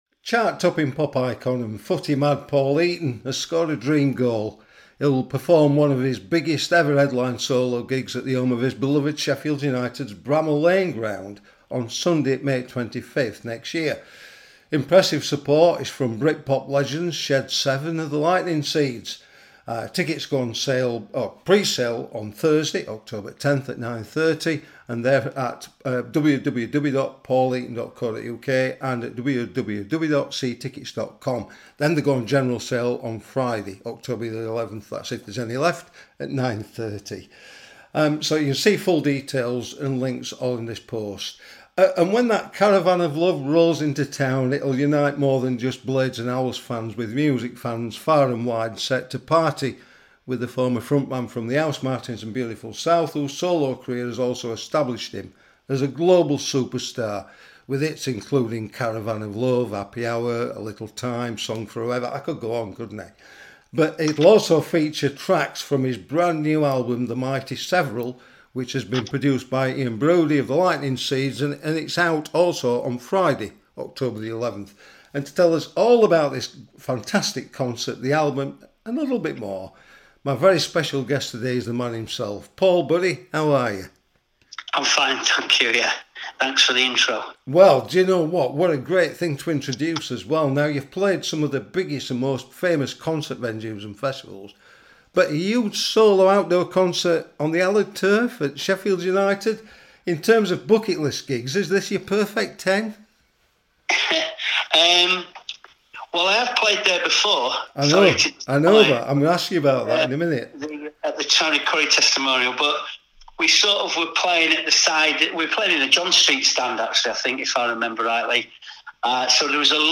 INTERVIEW: Paul Heaton reveals 'homecoming' Bramall Lane gig to unite fans